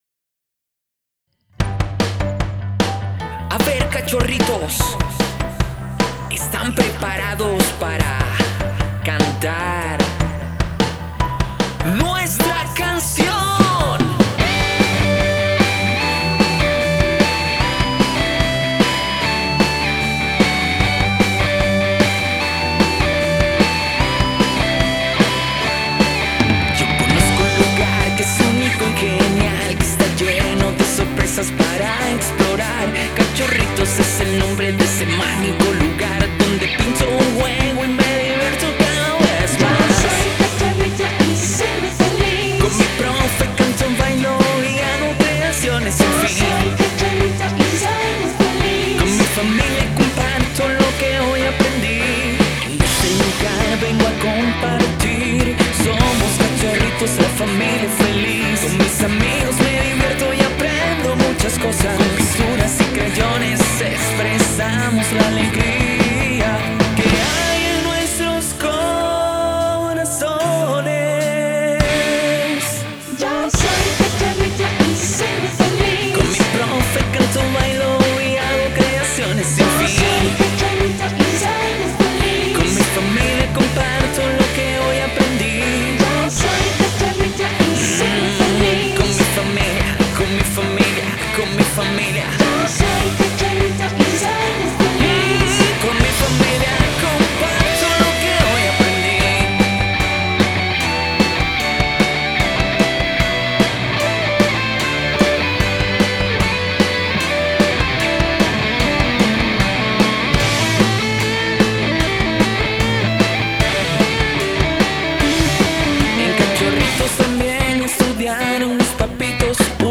Himno
Himno-Preescolar-Cachorritos.mp3